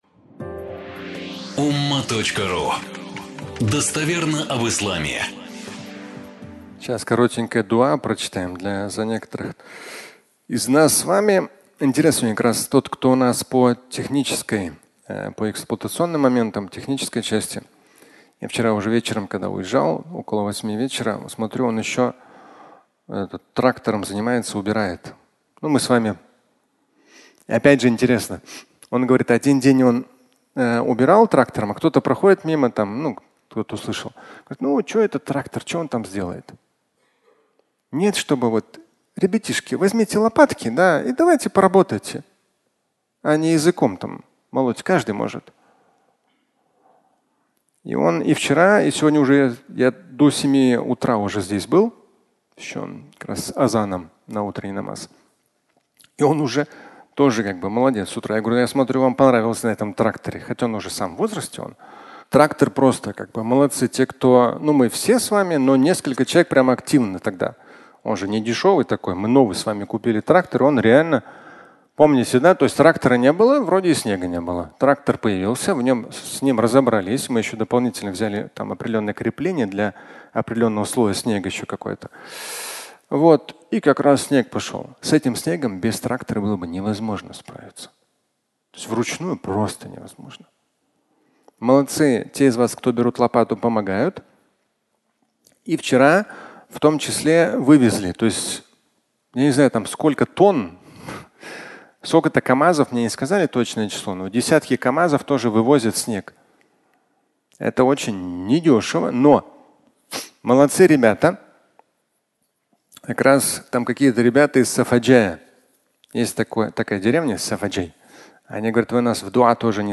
Благие дела (аудиолекция)